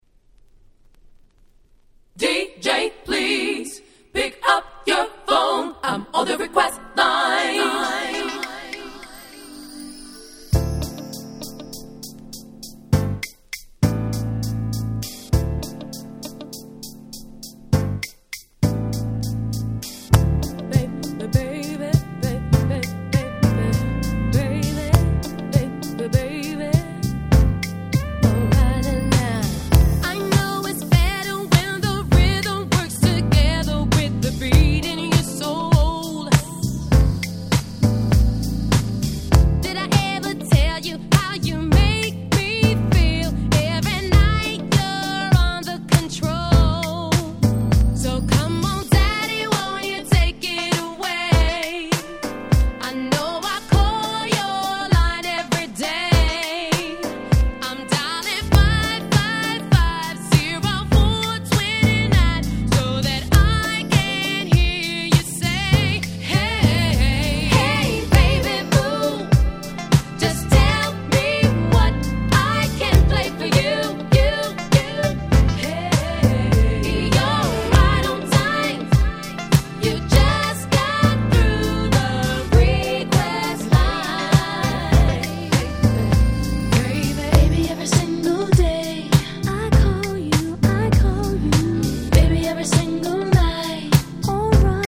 ※試聴ファイルは別の盤から録音してあります。
97' Smash Hit R&B !!